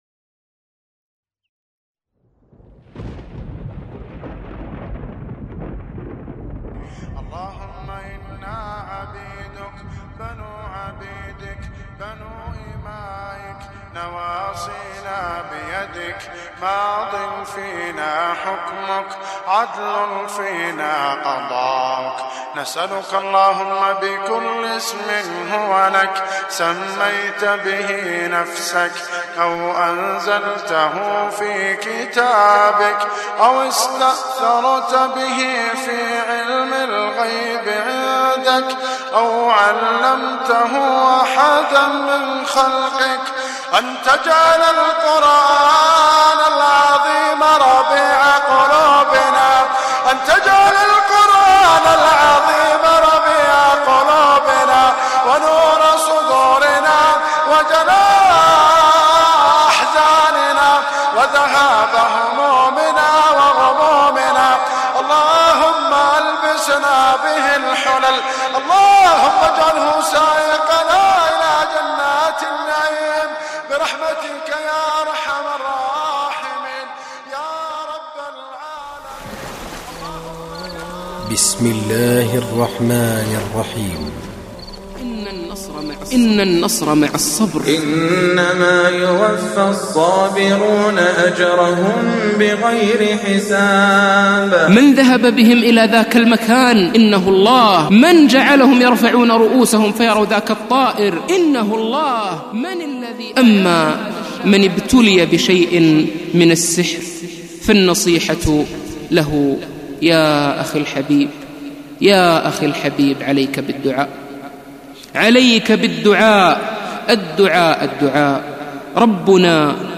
أصيب بالمس ـ تلاوة ياسر الدوسري - قسم أغســــل قلــــــبك1